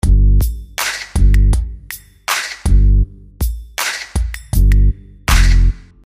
Drum Loops " 嘻哈节拍
描述：肮脏和有效果的HipHop节拍。BPM 90
标签： 替代 说唱 嘻哈 重节拍 击败 独立-街舞 低音 气氛
声道立体声